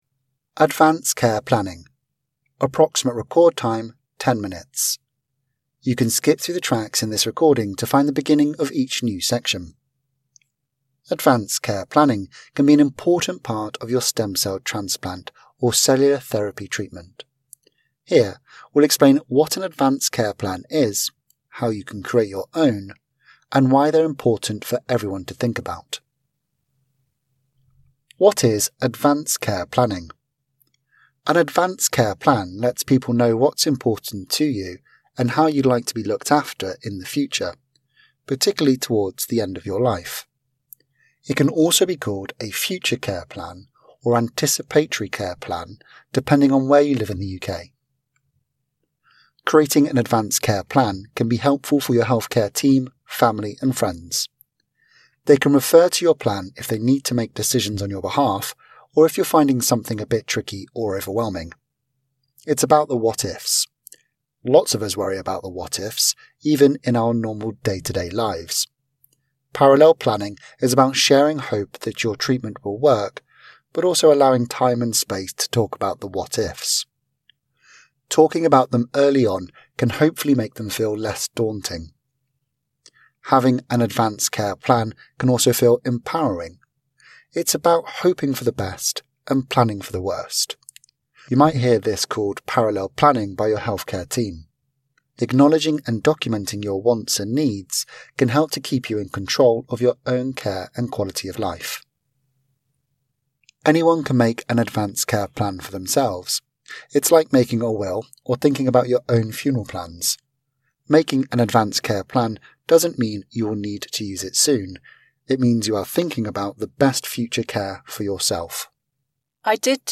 Audio version of Anthony Nolan's patient information: Advance care planning